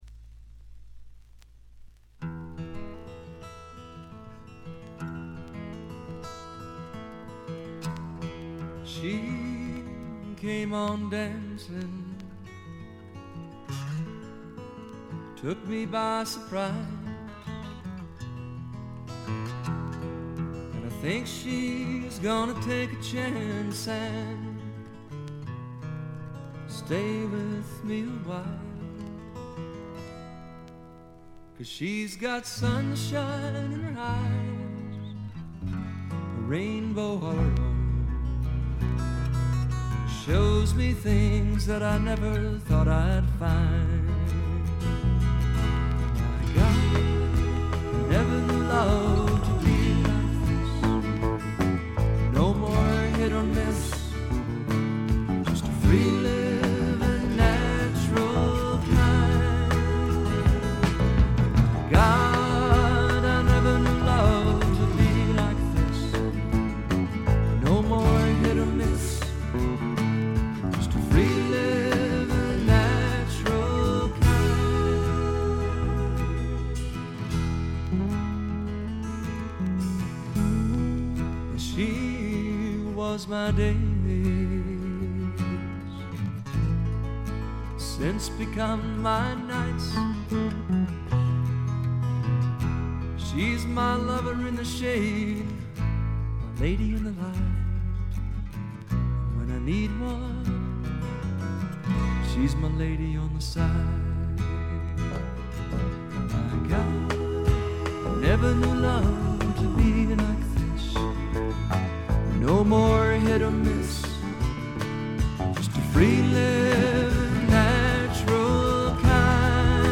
ところどころでバックグラウンドノイズ、チリプチ。
試聴曲は現品からの取り込み音源です。
Lead Vocals, Acoustic Guitar